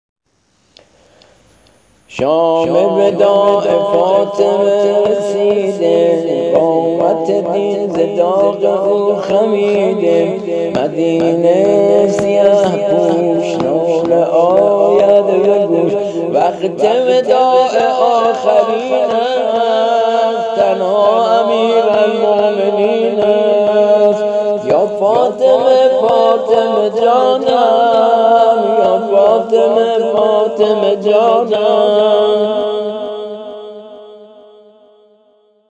◾نوحه سینه زنی (سبک سنتی)